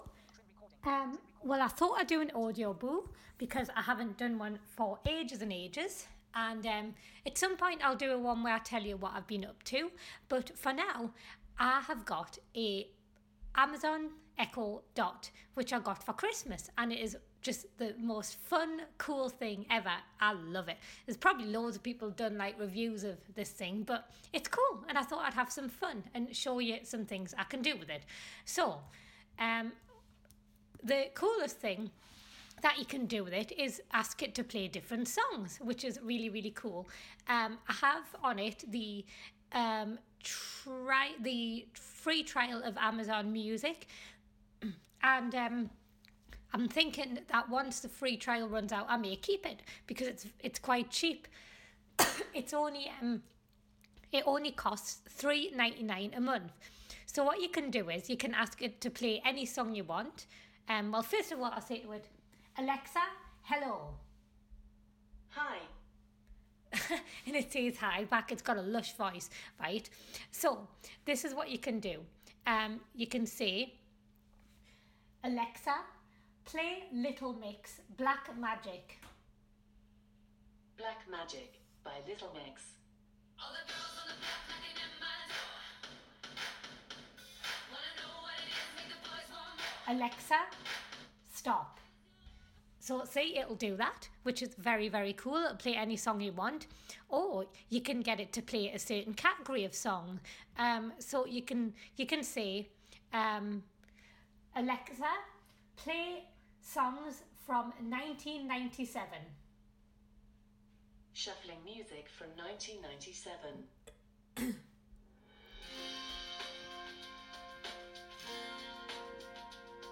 This is me demonstrating the Amazon Echo dot and having some fun with it.